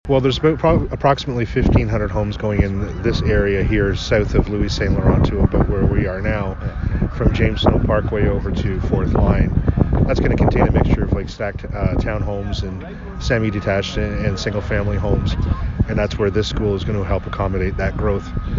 Milton MPP Parm Gill held a press conference at the building site along James Snow Parkway, just south of Louis St. Laurent Avenue on Monday morning.